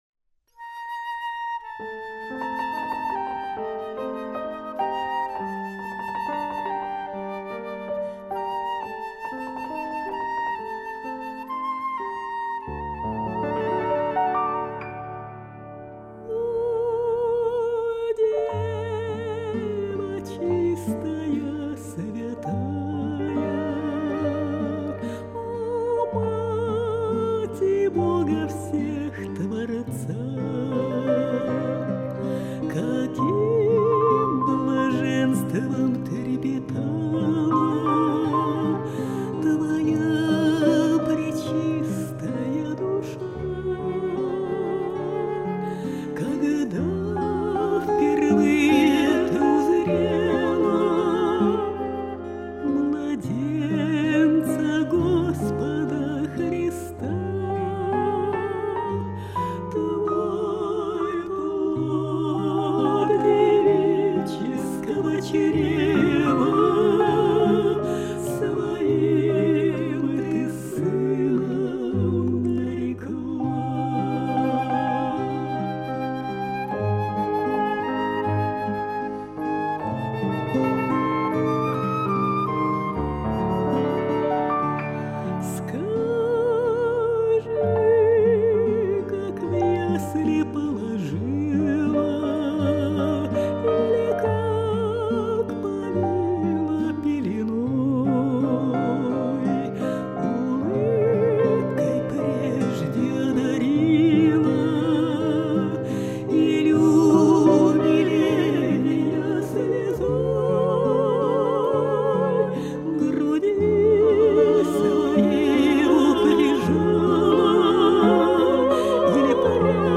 Духовная музыка
Она обладает глубоким лирико - драматическим меццо-сопрано.